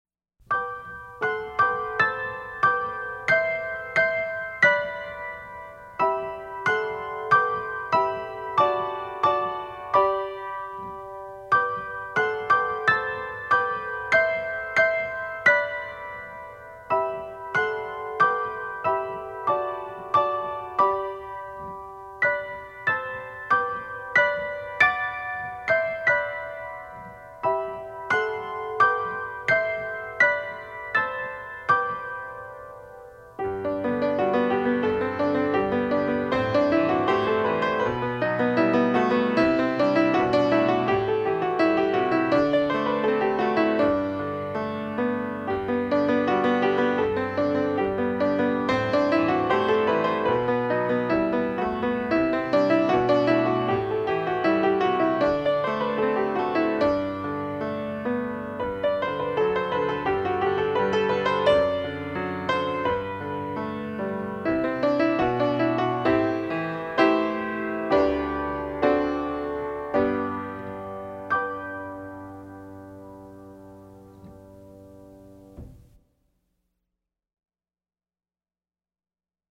DIGITAL SHEET MUSIC - PIANO SOLO